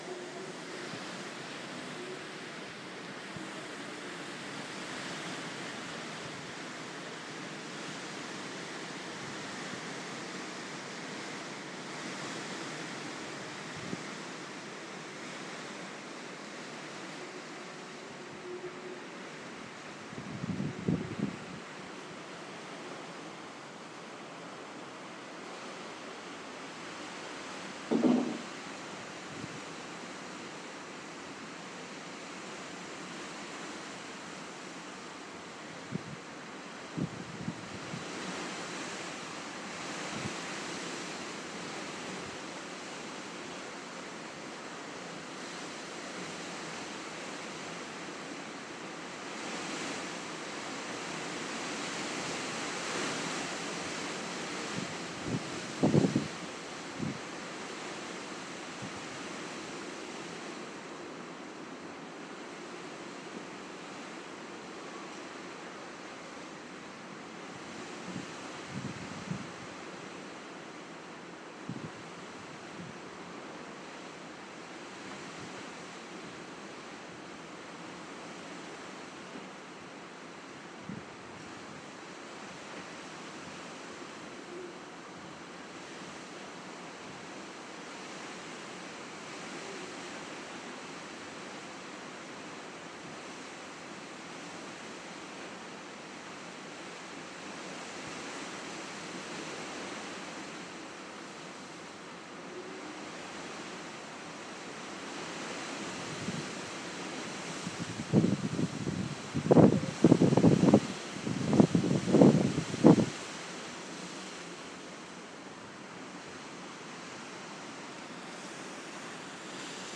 St Jude at Port Solent
Gale